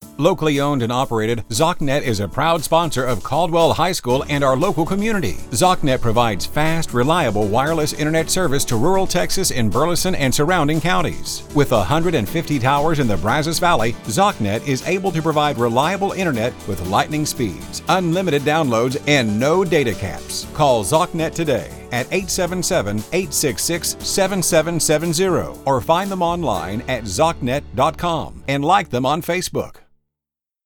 caldwell-radio-ad.mp3